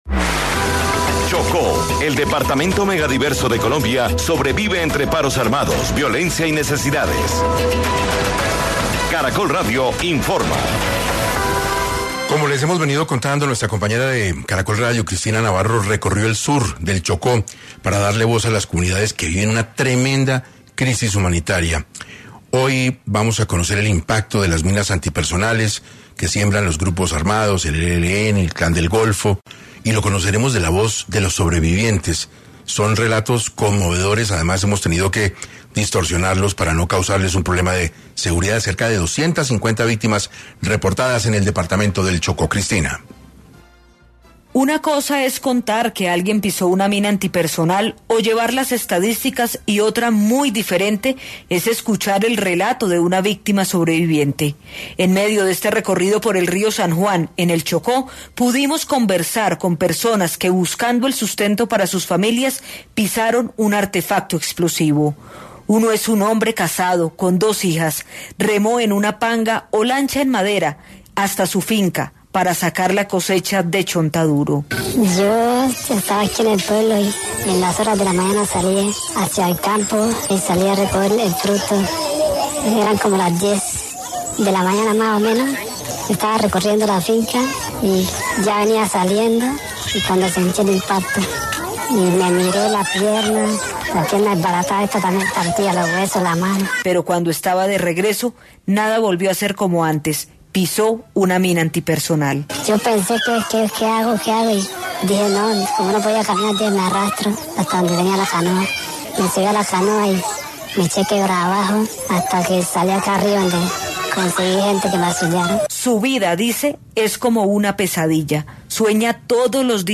En medio de este recorrido de Caracol Radio por el río San Juan, en el sur del Chocó, pudimos conversar con personas que buscando el sustento para sus familias pisaron un artefacto explosivo.